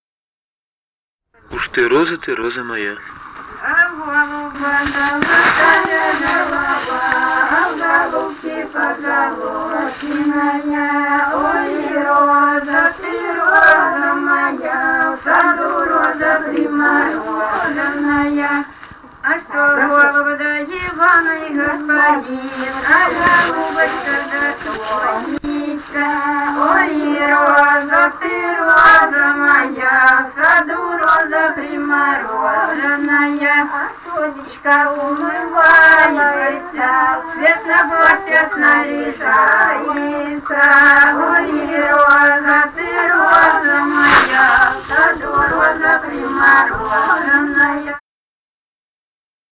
Файл:63 А в голуба золотая голова (свадебная) И0871-25 Митьковка.mp3 — Фолк депозитарий